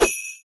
sh_bell_c_3.wav